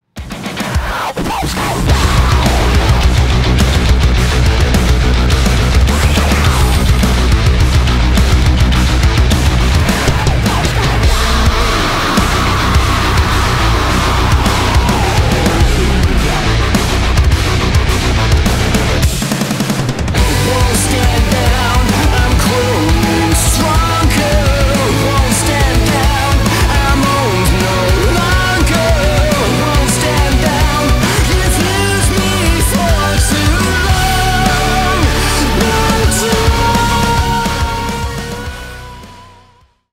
Рок Металл
злые # громкие